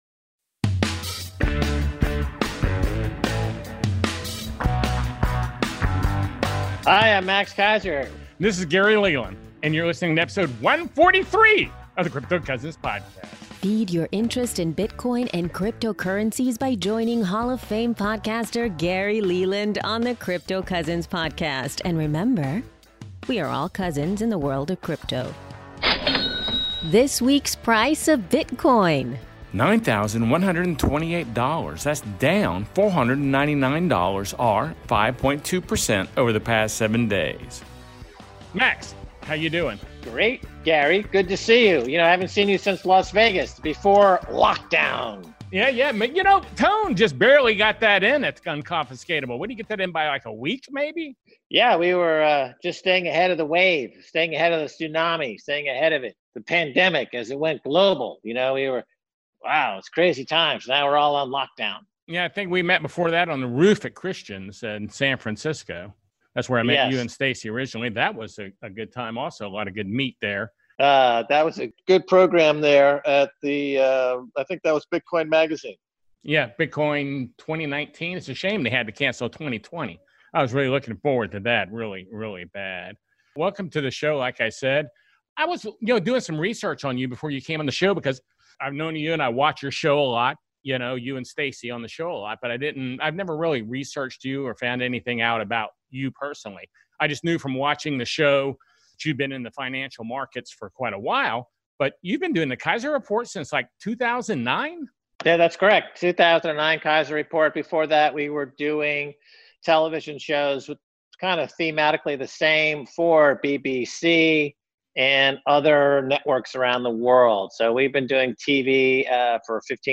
I had a great visit with Max Keiser about his experience with digital scarcity, the imminent global hash war, and the Cantillon effect.